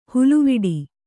♪ huluviḍi